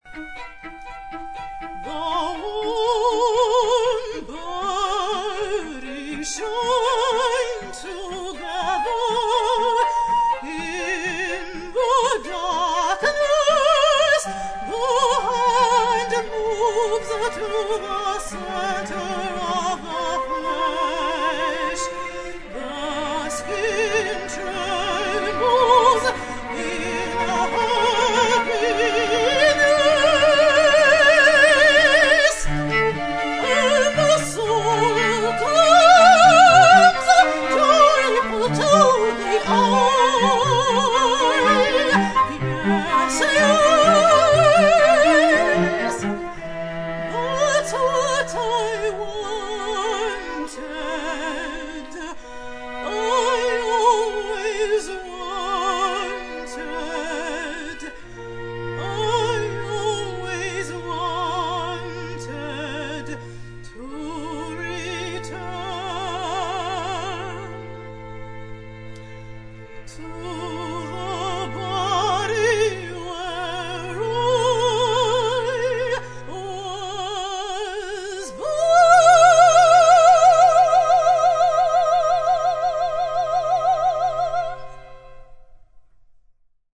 An unusual melodic setting of Ginsberg's poetry